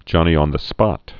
(jŏnē-ŏnthə-spŏt, -ôn-)